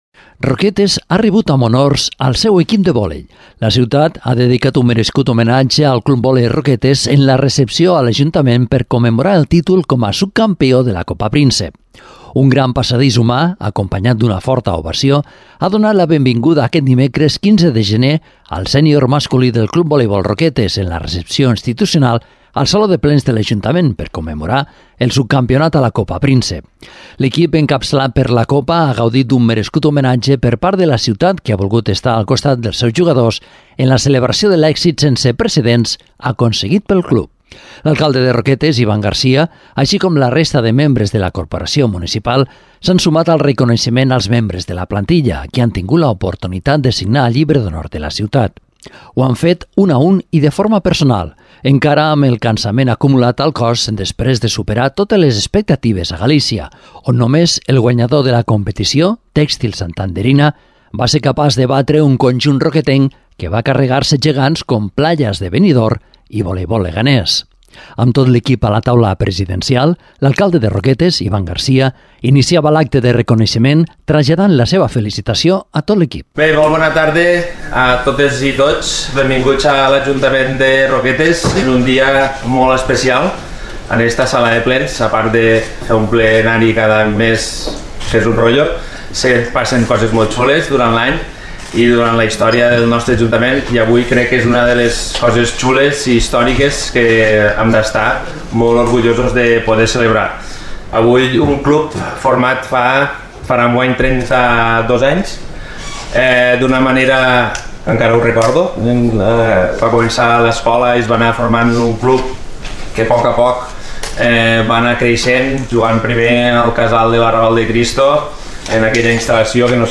Un gran passadís humà, acompanyat d’una forta ovació, ha donat la benvinguda aquest dimecres al Sènior masculí del Club Voleibol Roquetes, en la recepció institucional al Saló de Plens de l’Ajuntament per commemorar el subcampionat a la Copa Príncep.